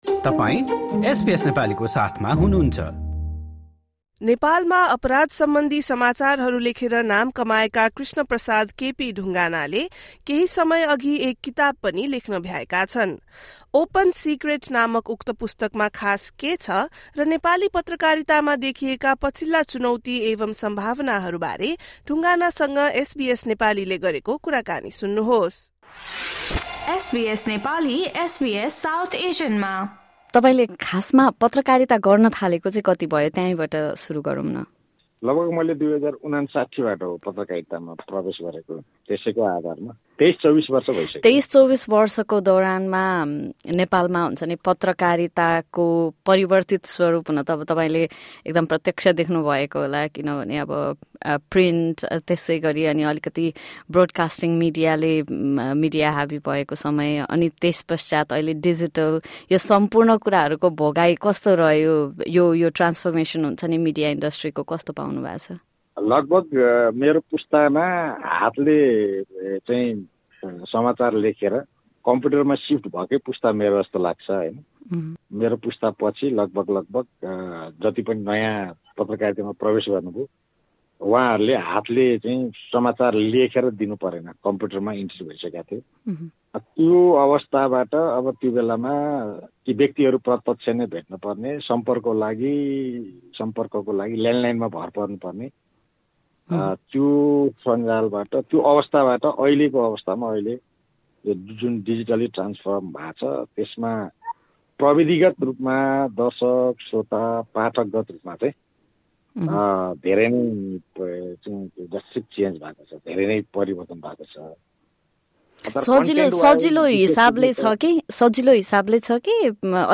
एसबीएस नेपालीले गरेको कुराकानी सुन्नुहोस्।